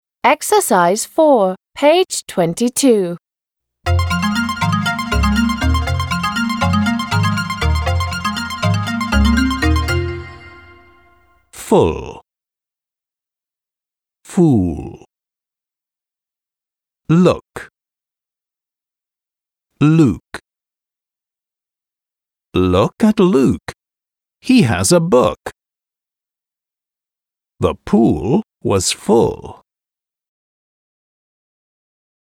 /ʊ/ − краткий звук «у» в закрытом слоге (в том числе «оо» + «k»):
/uː/ − более длинный звук «у» (в том числе «оо»):